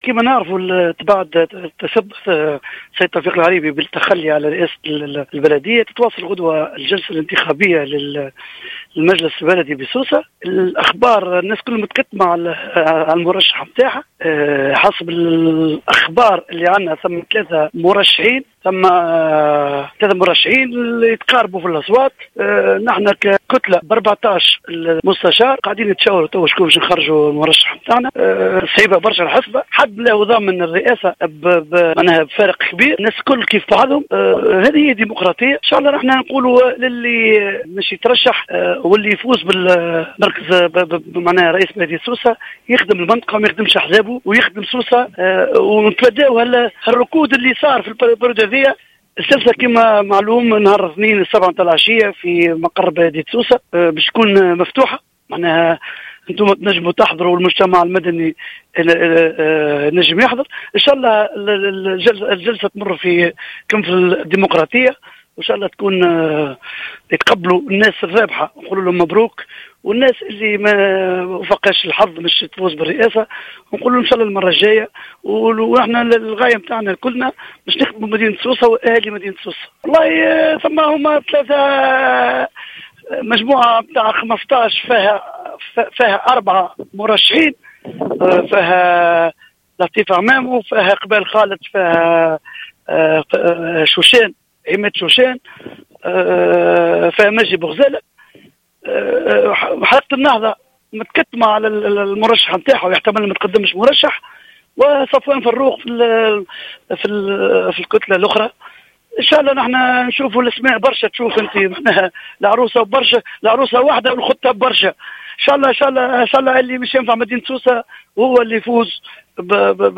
أكد عضو المجلس البلدي لبلدية سوسة مكرم اللقام في تصريح للجوهرة "اف ام" أنه سيتم استئناف الجلسة الإنتخابية غدا ببلدية سوسة وسط تكتم كبير على المرشحين .